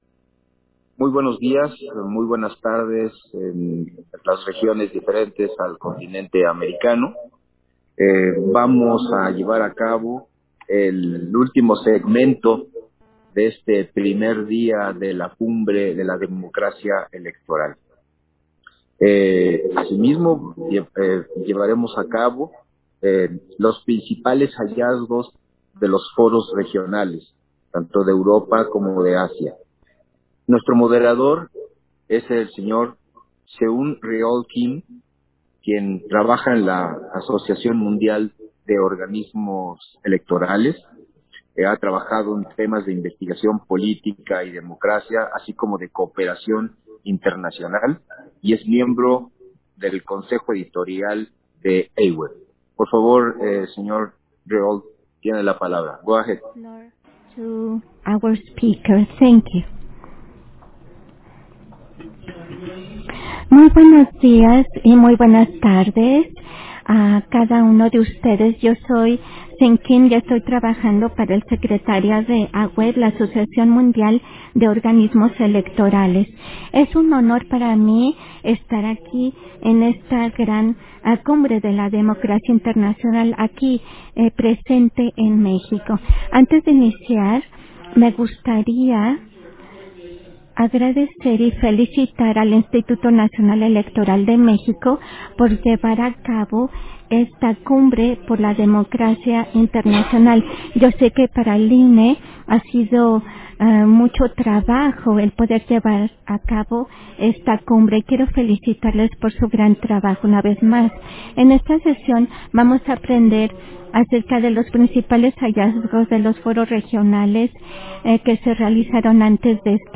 Versión estenográfica del panel: Principales hallazgos de los foros regionales, en el marco de la Cumbre Global de la Democracia Electoral